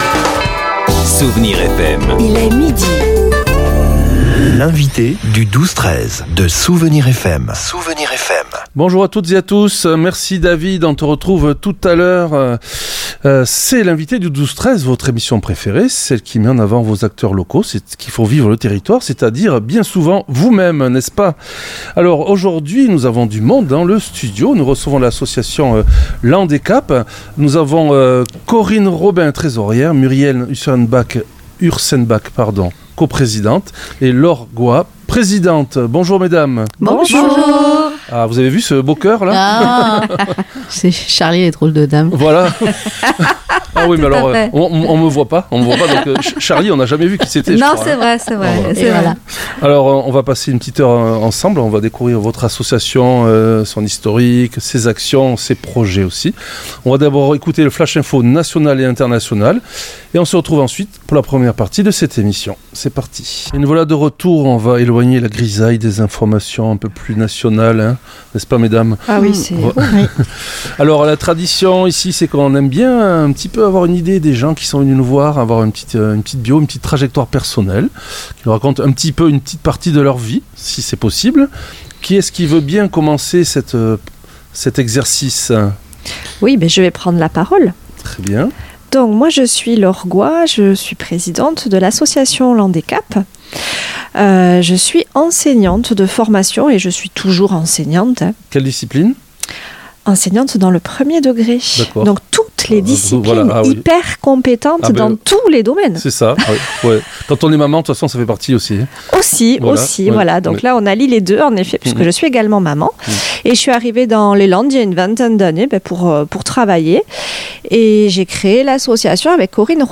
L'entretien a mis en lumière un calendrier solidaire très riche pour 2026.